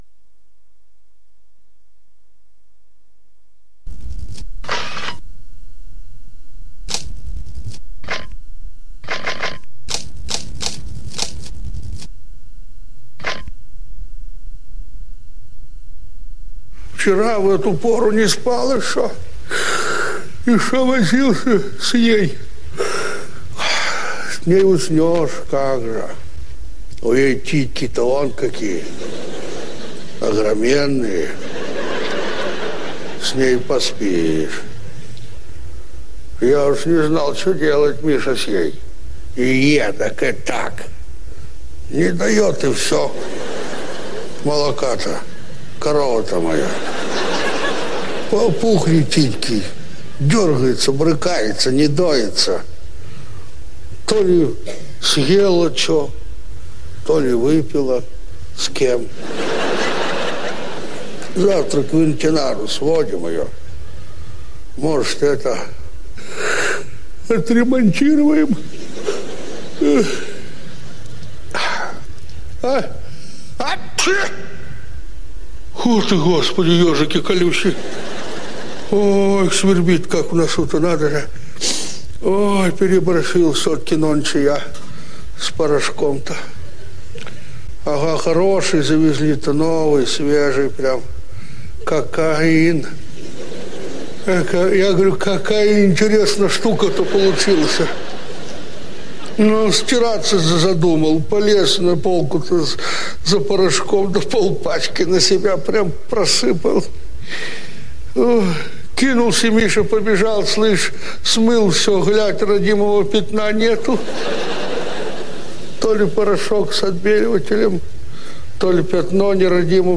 Несколько редких записей из выступлений М.Евдокимова.
Юмор